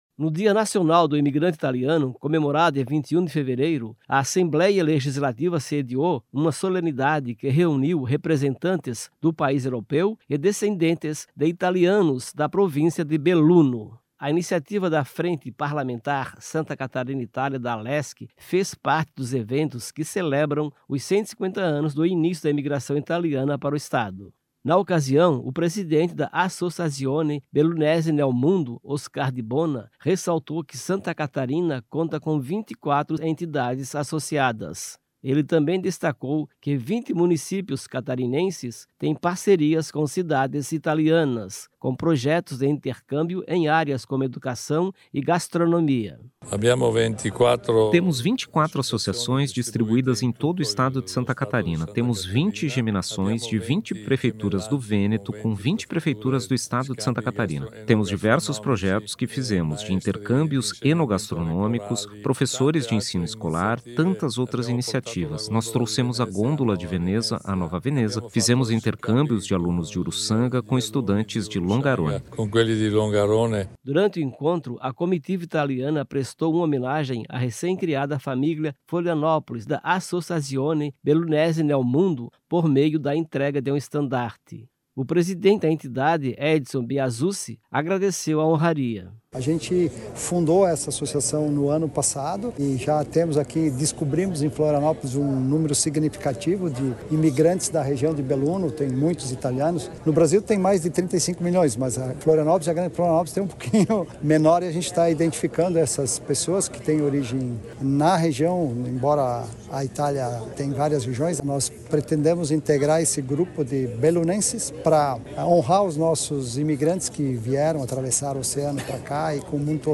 Entrevistas com: